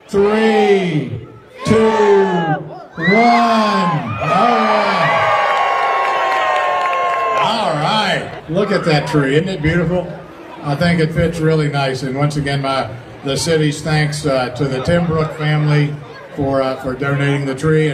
A chilly night in downtown Cumberland Friday as the community gathered to light the annual Christmas tree at the corner of Liberty and Baltimore Street.
Cumberland Mayor, Ray Morriss led the crowd in a countdown for the tree lighting…